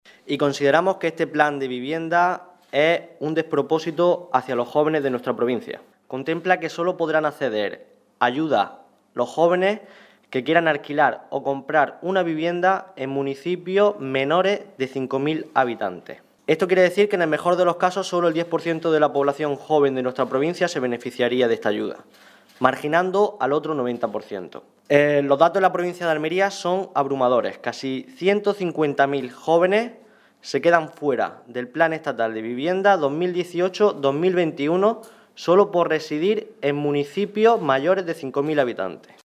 Rueda de prensa que ha ofrecido el PSOE de Almería sobre vivienda